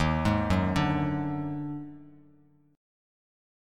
EbmM7bb5 chord